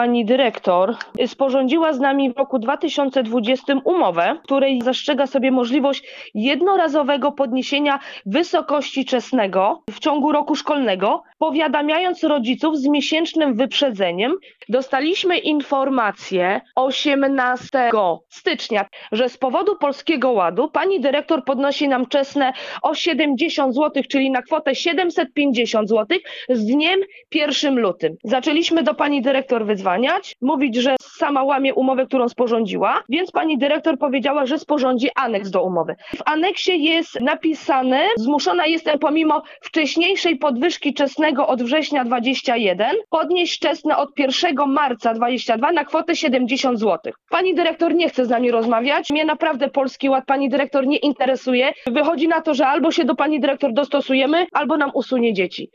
Mówi mieszkanka Stargardu.